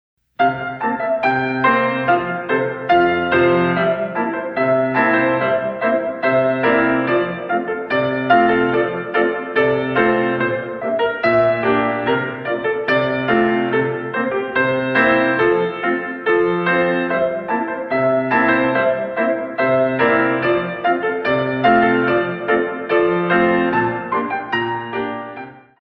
In 2
64 Counts